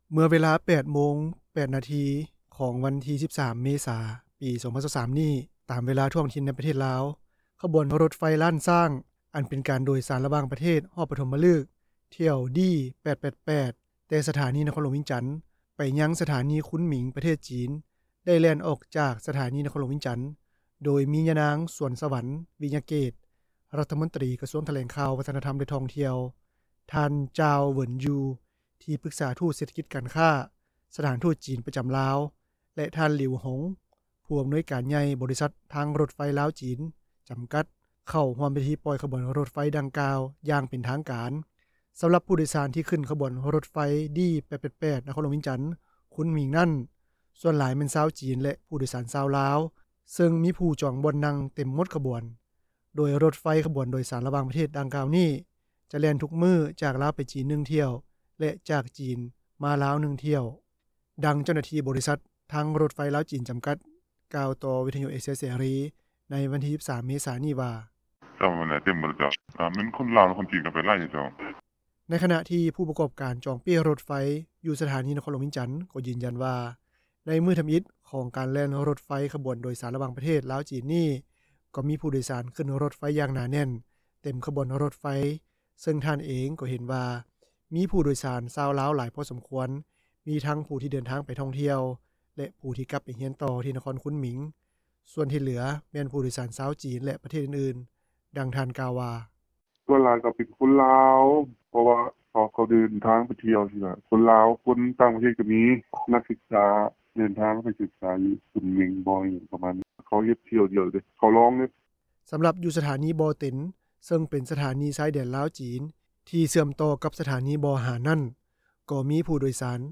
ດັ່ງເຈົ້າໜ້າທີ່ບໍຣິສັດ ທາງຣົຖໄຟລາວ-ຈີນ ຈຳກັດ ກ່າວຕໍ່ວິທຍຸ ເອເຊັຽເສຣີ ໃນວັນທີ 13 ເມສາ ນີ້ວ່າ:
ດັ່ງຜູ້ປະກອບການຈອງປີ້ຣົຖໄຟ ຢູ່ສະຖານີຫຼວງພຣະບາງ ກ່າວວ່າ: